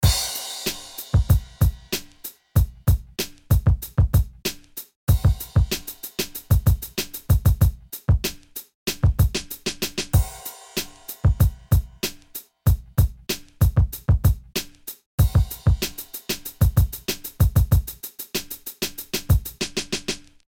鼓点节拍循环
描述：鼓点节拍循环。 非常适合嘻哈音乐制作人。
标签： 节拍 hop 循环 样品
声道立体声